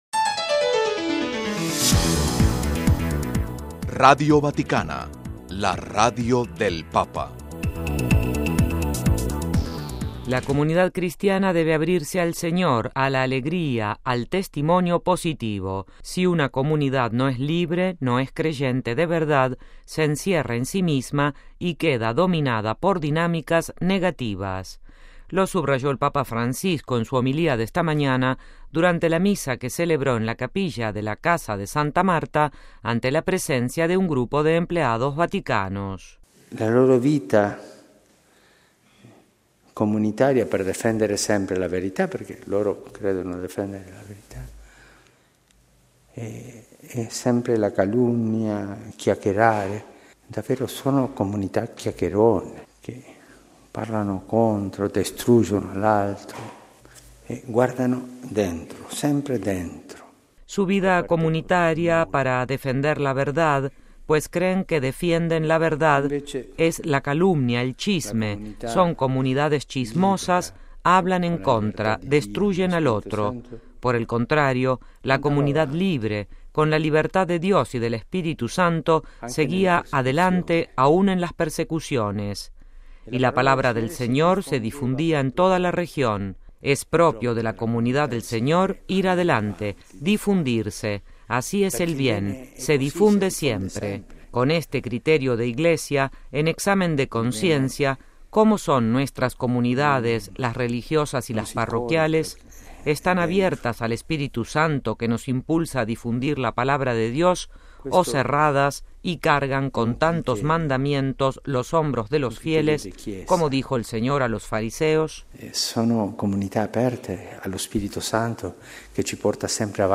Si una comunidad no es libre, no es creyente de verdad, se encierra en sí misma y queda dominada por dinámicas negativas. Lo subrayó el Papa Francisco en su homilía del sábado 27 de abril por la mañana durante la misa que celebró en la capilla de la casa de Santa Marta ante la presencia de un grupo de empleados vaticanos del Servicio de Correo y un grupo de voluntarios del dispensario pediátrico “Santa Marta”.